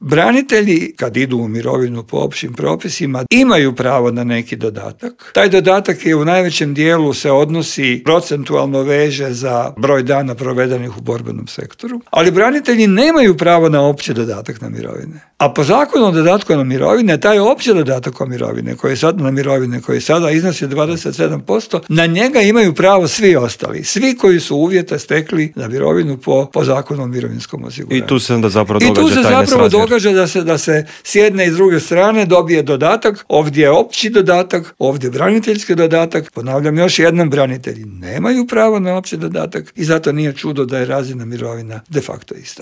Saborski zastupnik iz redova platforme Možemo! Damir Bakić u Intervjuu Media servisa poručio je da će se povećanje cijena goriva preliti i na druga poskupljenja: "Teret ove krize podnijet će građani i umirovljenici".